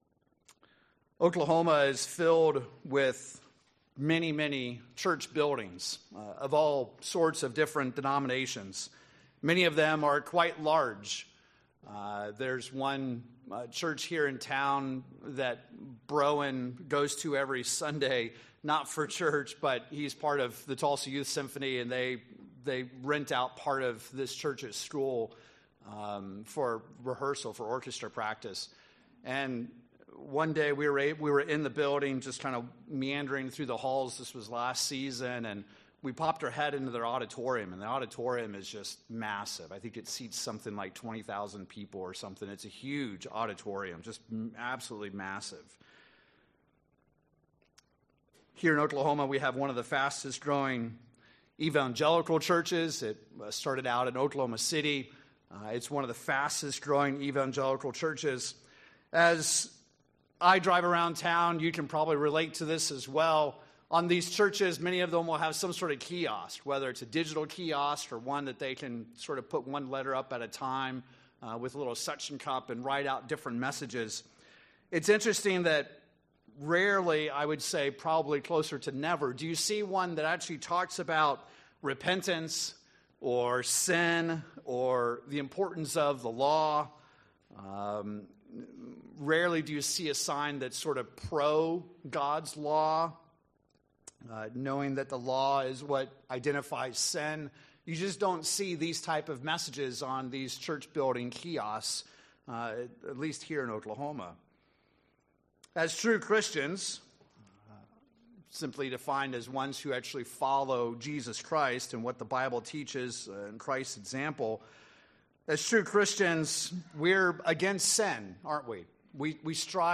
In this sermon we examine which laws of God we are absolutely commanded to keep today. These specific laws are eternal and constant.